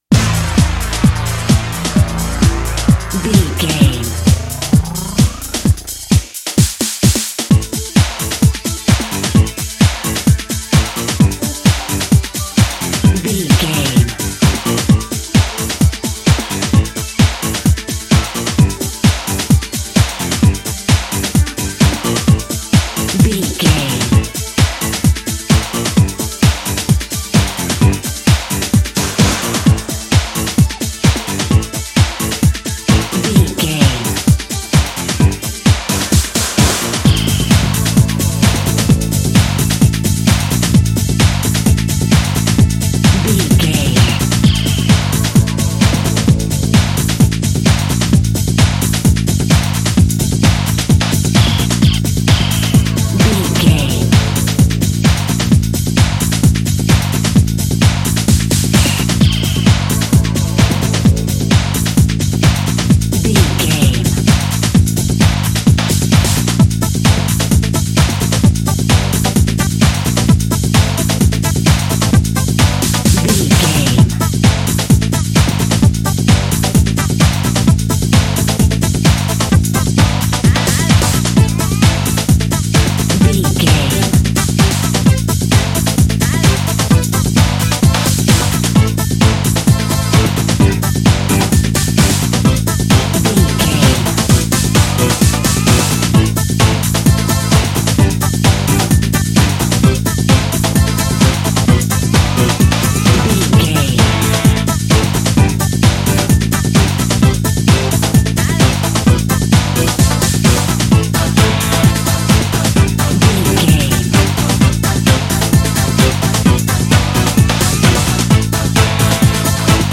Aeolian/Minor
synthesiser
drum machine
90s
Eurodance